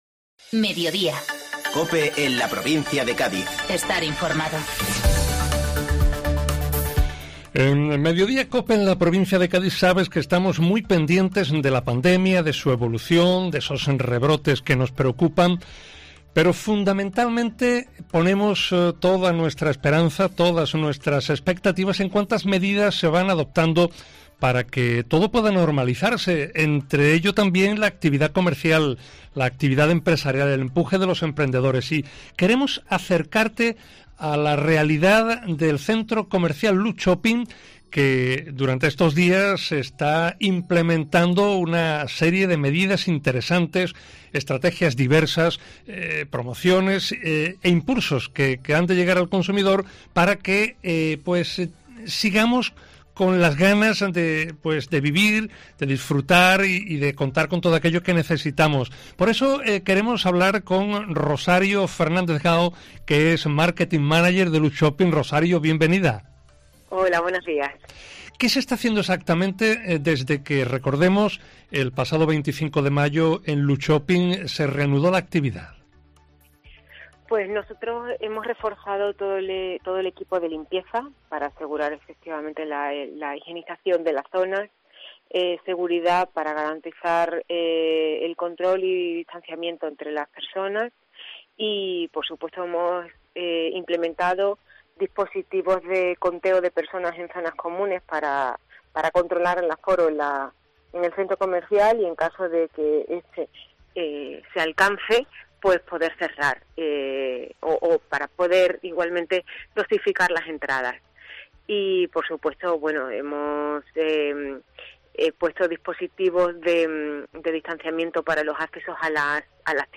Entrevista en Mediodía Cope Provincia de Cádiz